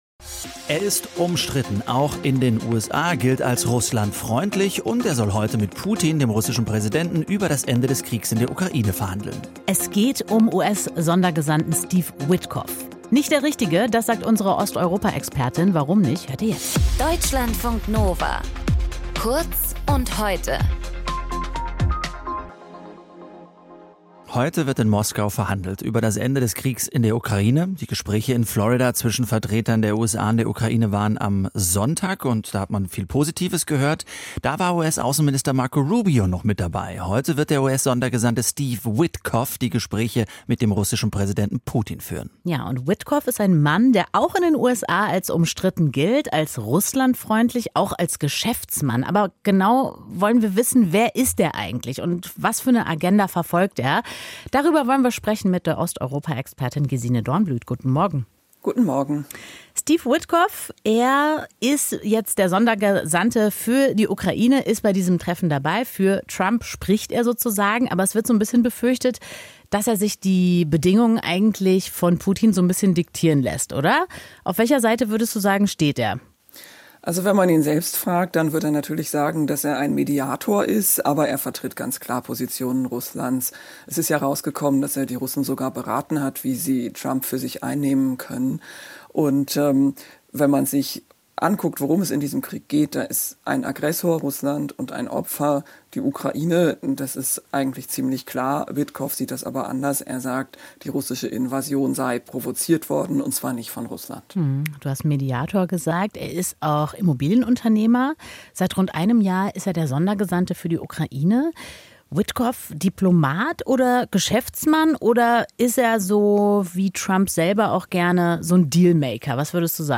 Moderation:
Osteuropa-Expertin bei Deutschlandradio